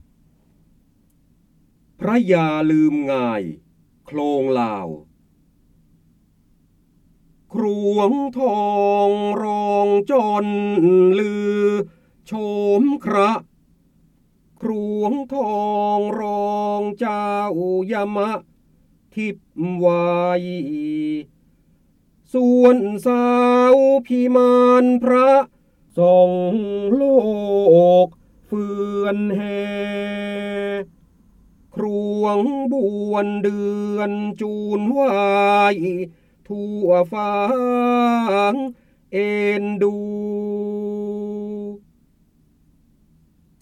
เสียงบรรยายจากหนังสือ จินดามณี (พระโหราธิบดี) พระยาลืมงายโคลงลาว
คำสำคัญ : ร้อยกรอง, ร้อยแก้ว, พระโหราธิบดี, การอ่านออกเสียง, จินดามณี, พระเจ้าบรมโกศ